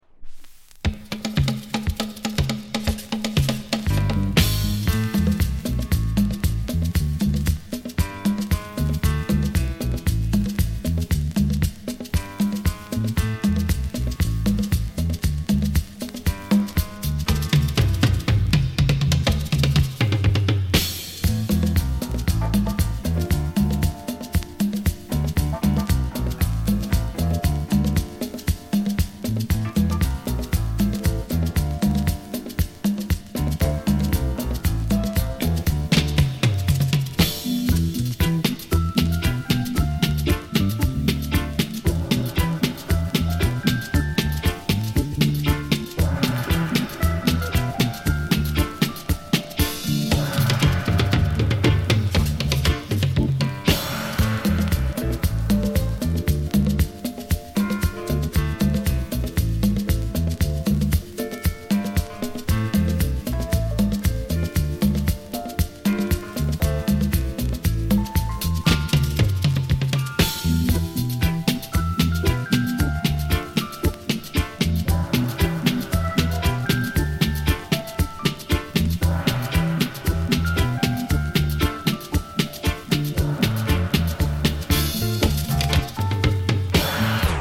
DUB, セサミストリート *
多少 ヒス・ノイズ 乗りますが、曲の間はほぼ気になりません。